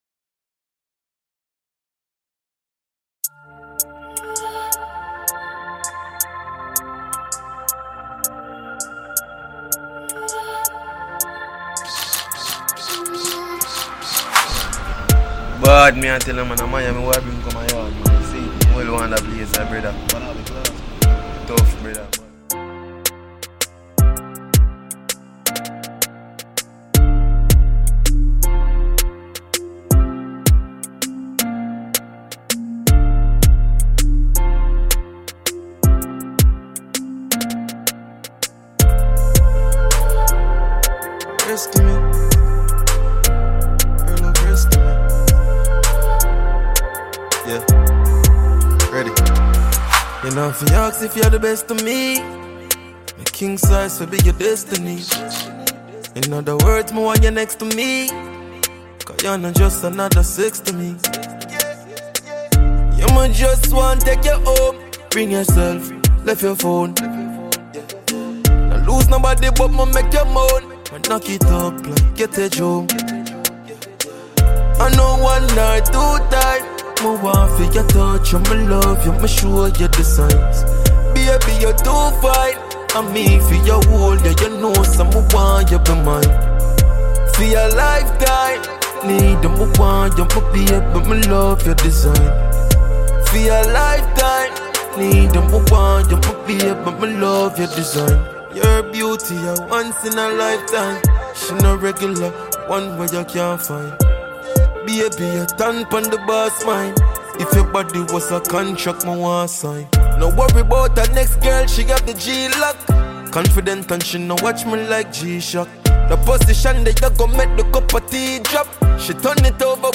Jamaican Reggae Dancehall singer/songwriter
love song